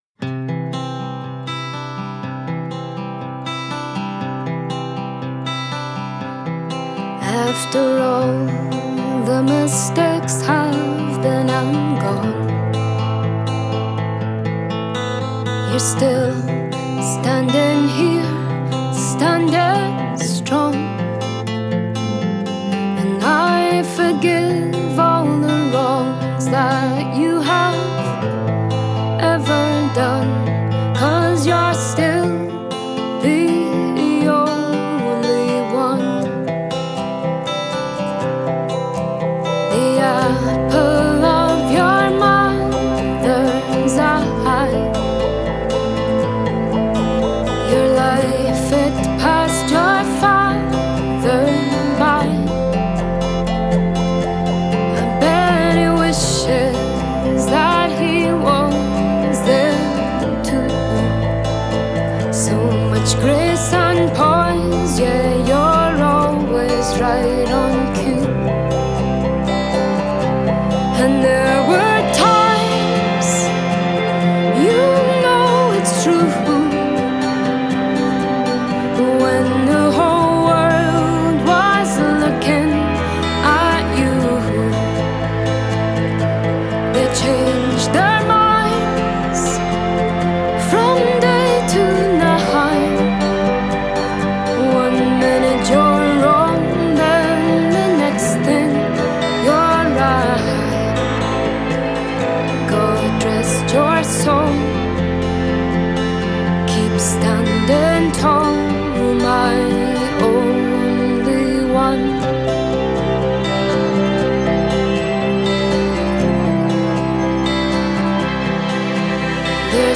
Фолк